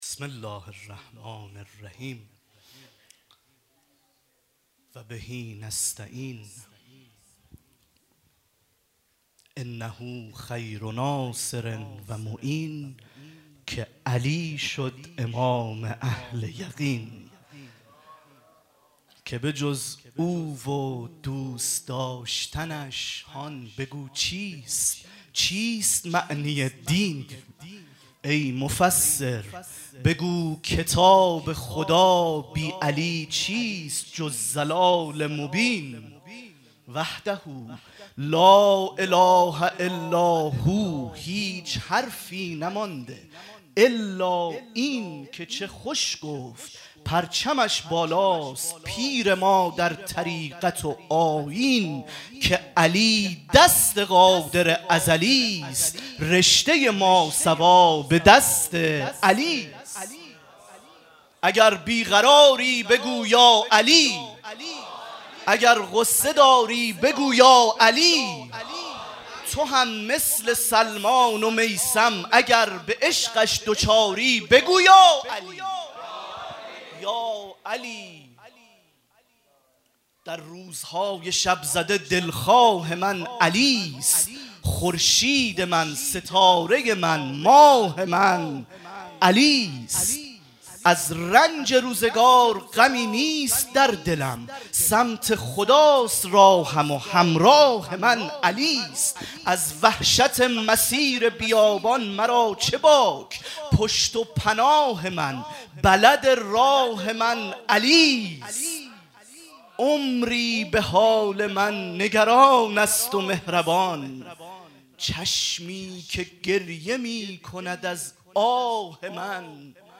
مراسم مناجات خوانی شب بیستم ماه رمضان 1444
شعر خوانی- بسم الله الرحمن الرحیم، و به نستعین